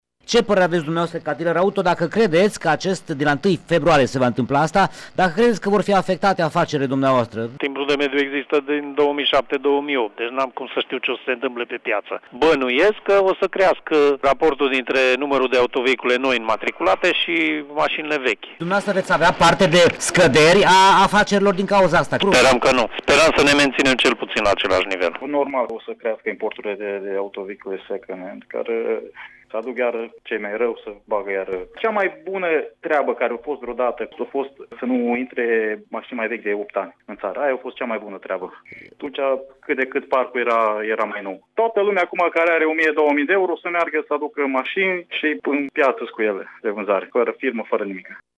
Pe de altă parte, dealerii auto cred că vor înflori, din nou, vânzările ilegale în piețele de mașini: